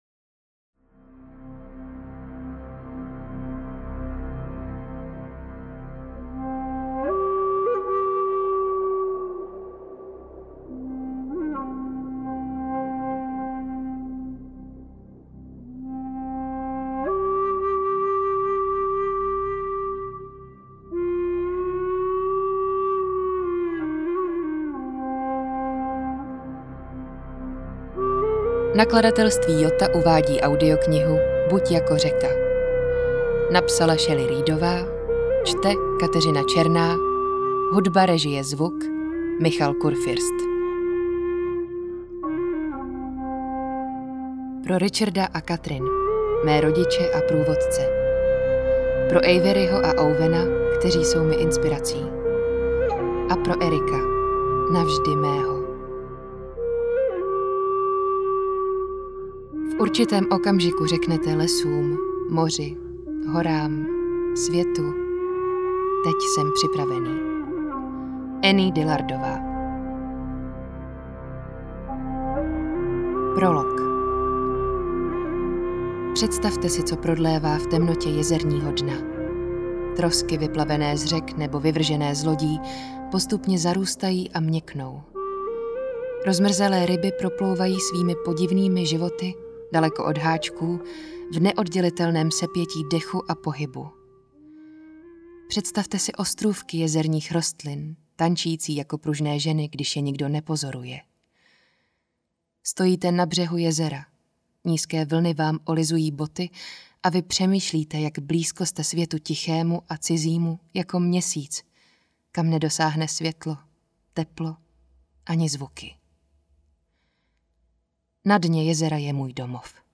Buď jako řeka audiokniha
Ukázka z knihy